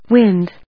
/wínd(米国英語)/